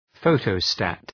{‘fəʋtə,stæt}